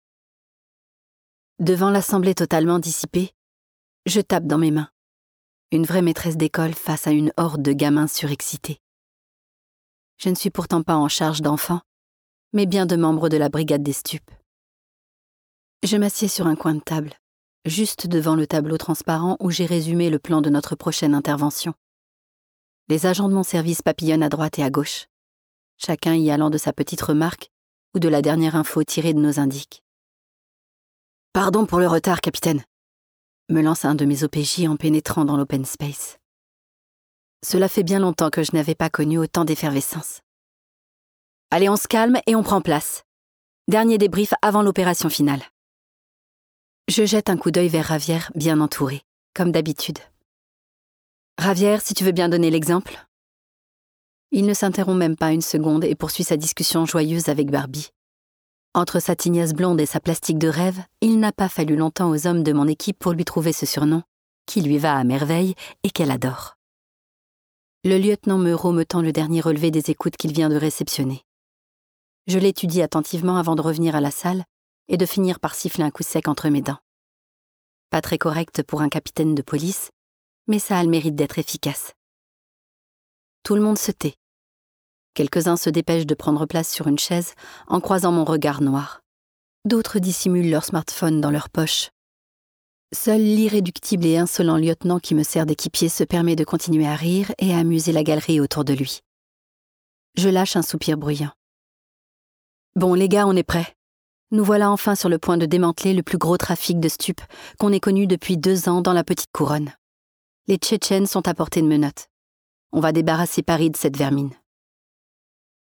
*IH ou Interprétation Humaine signifie que des comédiennes et comédiens ont travaillé à l'enregistrement de ce livre audio, et qu'aucune voix n'a été enregistrée avec l'intelligence artificielle.